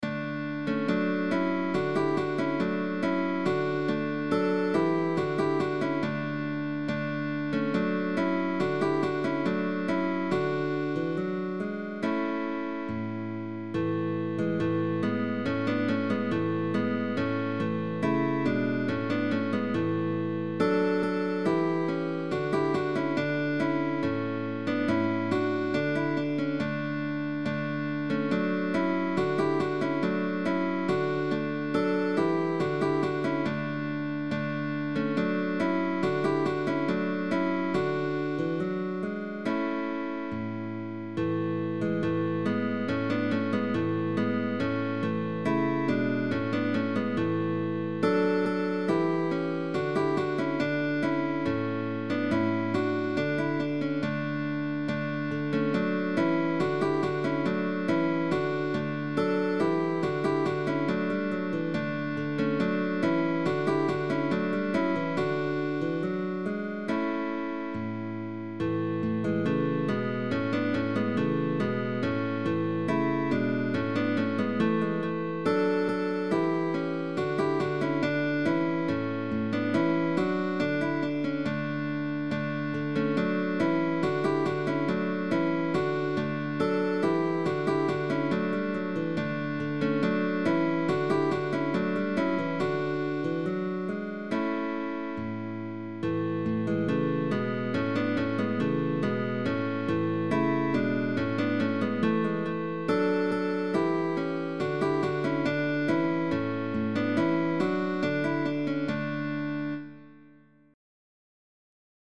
dance
Alternation of m and i. Rest Stroke. Thumb Melody bass.
GUITAR TRIO
Baroque